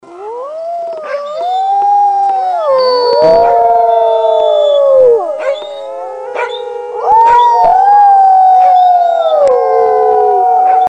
Howling
Dog-Howling.mp3